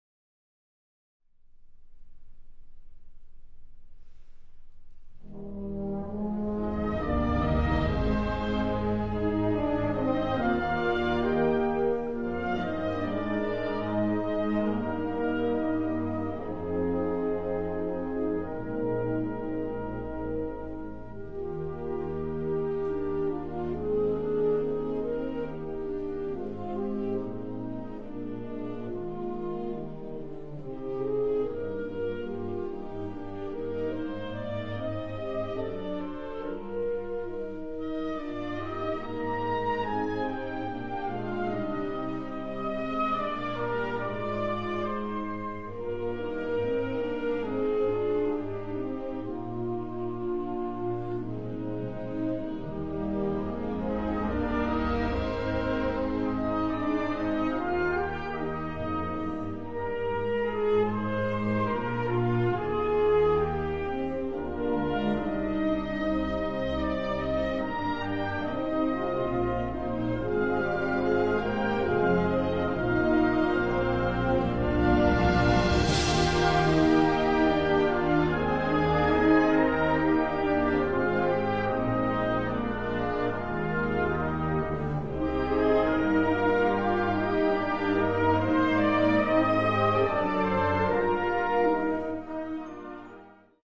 Partitions pour brass band.